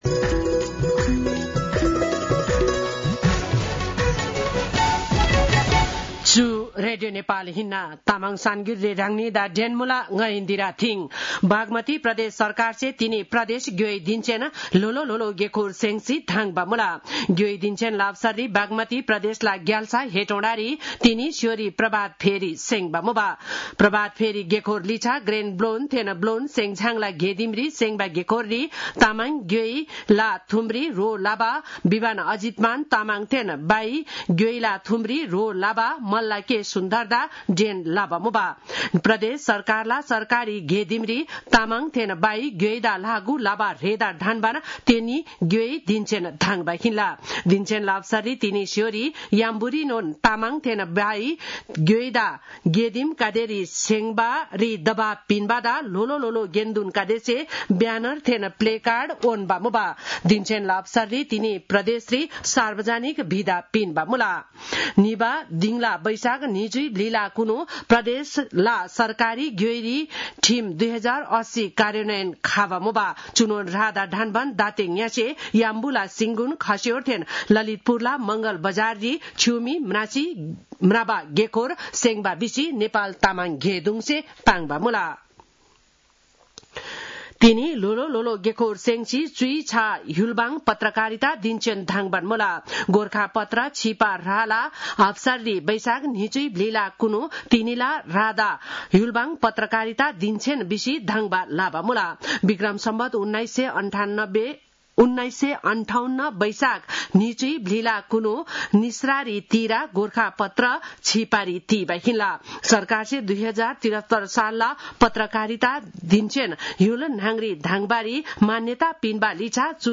तामाङ भाषाको समाचार : २४ वैशाख , २०८२